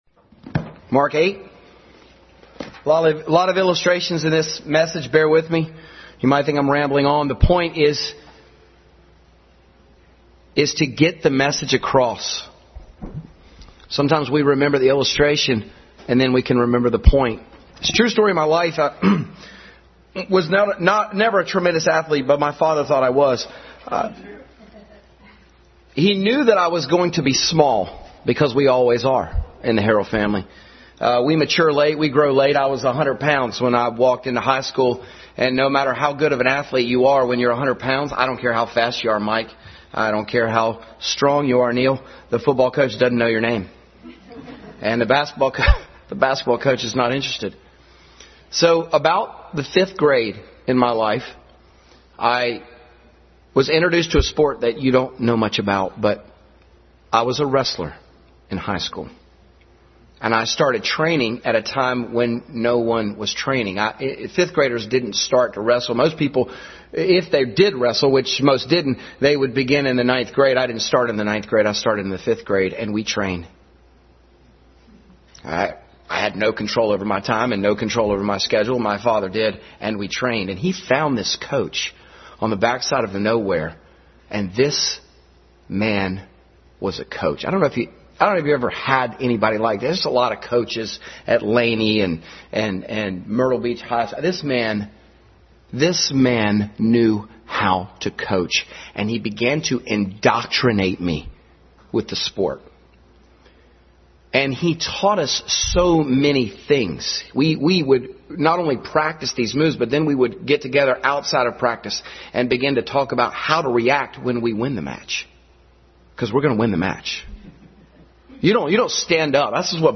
Family Bible Hour Message.
Mark 8:31 Service Type: Family Bible Hour Family Bible Hour Message.